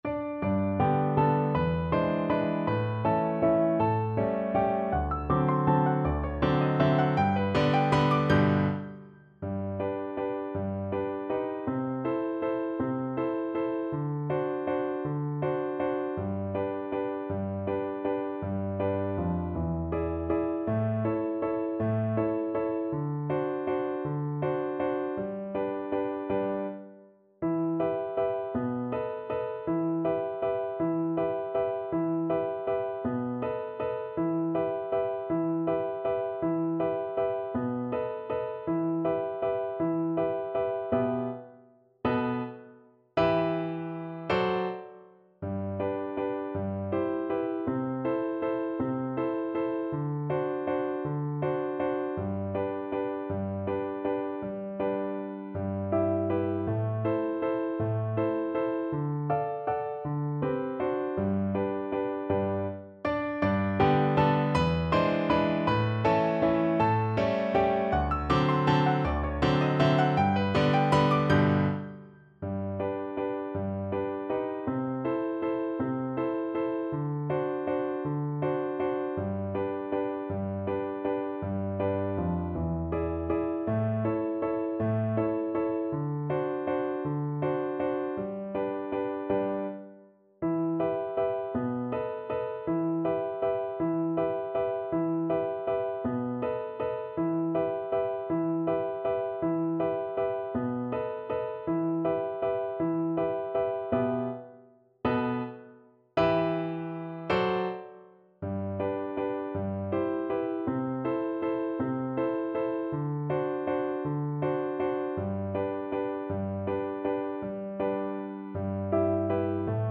3/4 (View more 3/4 Music)
G major (Sounding Pitch) (View more G major Music for Violin )
~ = 160 Tempo di Valse
Traditional (View more Traditional Violin Music)
flying_trapeze_VLN_kar1.mp3